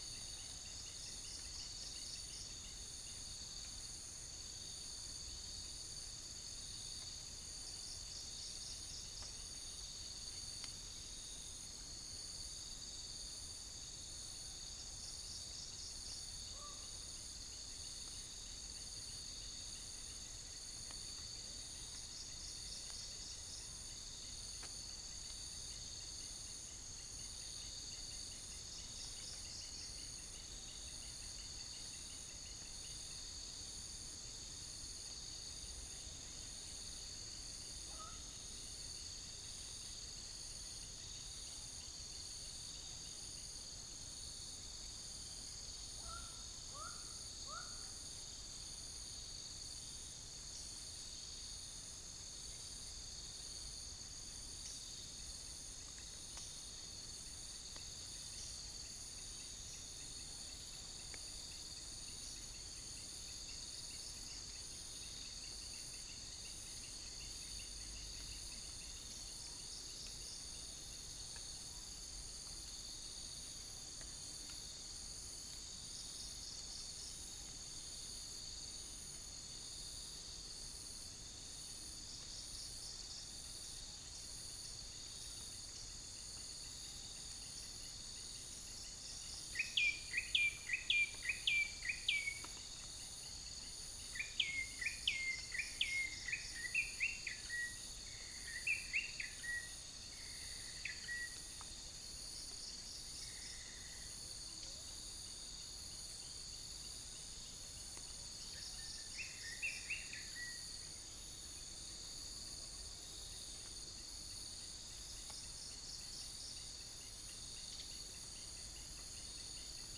Halcyon smyrnensis
Orthotomus sericeus
unknown bird